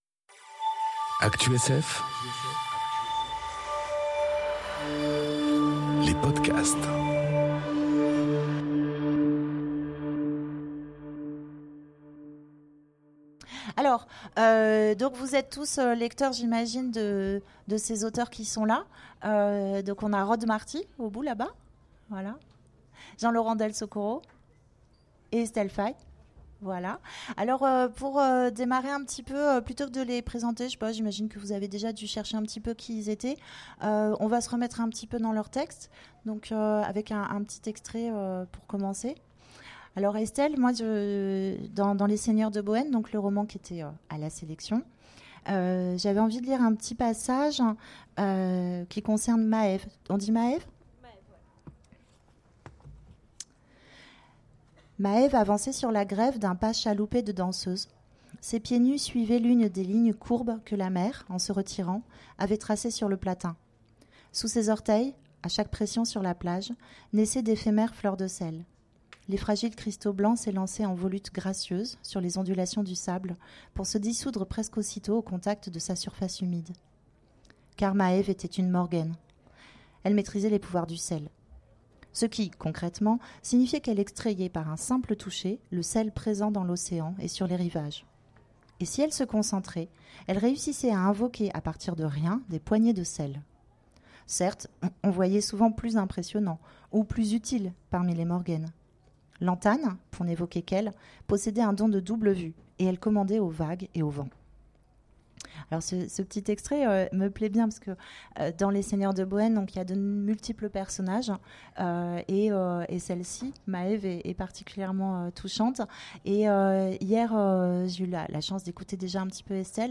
Conférence Prix Imaginales des Lycéens : la sélection 2018 à la question enregistrée aux Imaginales 2018